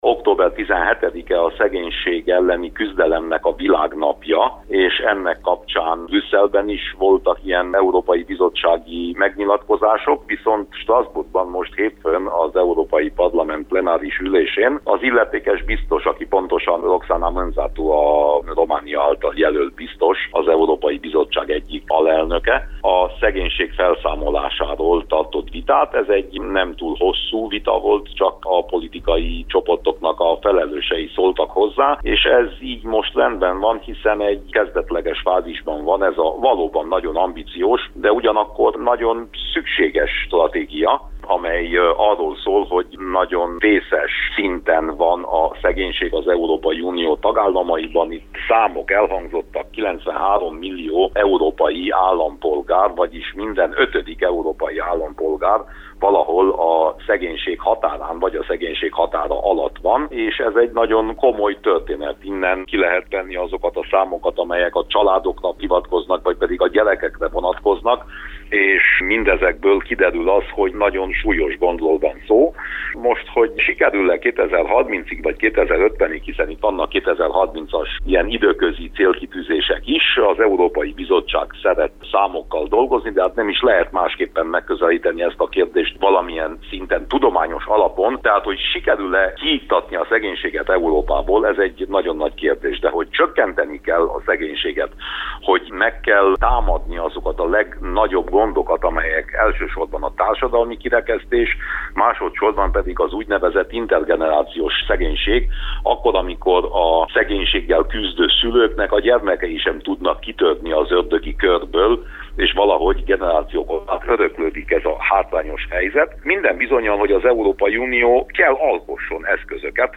A plenáris ülésen zajló vitáról Winkler Gyula EP képviselő számolt be a rádiónknak.